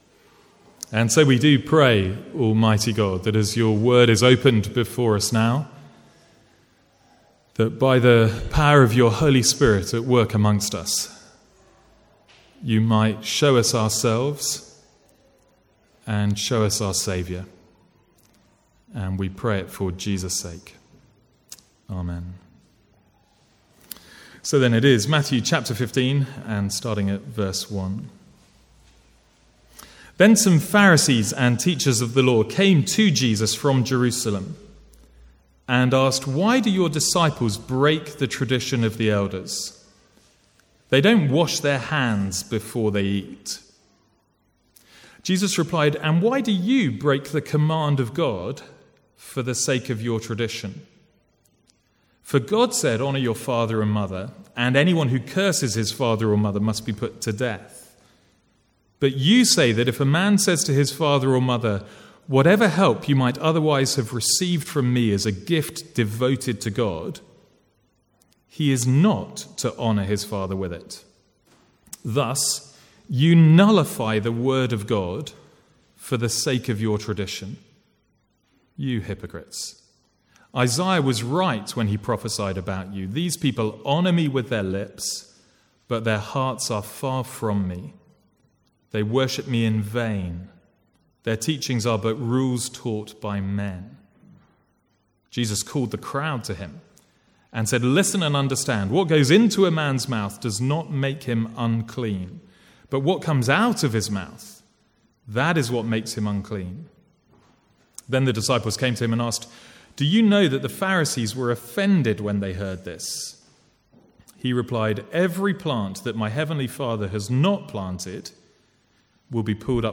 Sermons | St Andrews Free Church
From the Sunday morning series in Matthew (recorded 13/7/14).